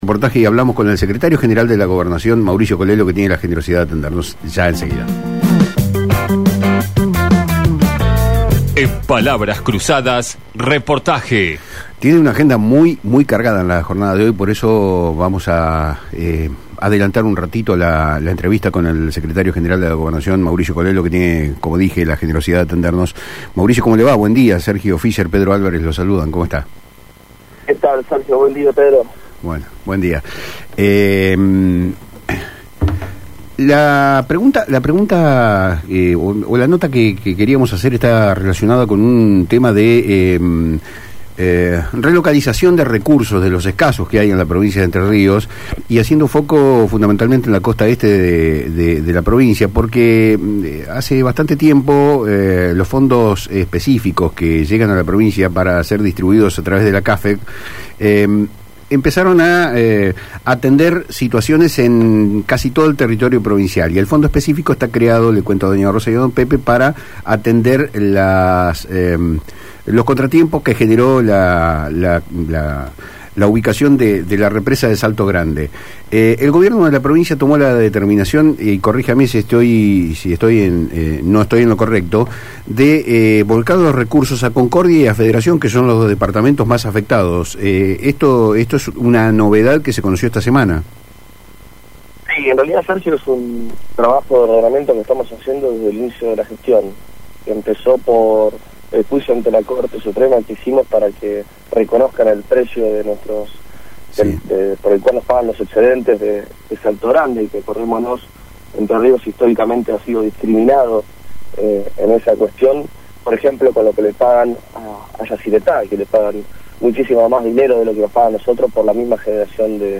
El Secretario General de la Gobernación de Entre Ríos, Mauricio Colello, explicó en diálogo con Palabras Cruzadas por FM Litoral el decreto mediante el cual se dispondrán más recursos para los departamentos más afectados por la represa de Salto Grande, garantizando financiamiento a Concordia y Federación, sin excluir a otros departamentos incluídos en la región.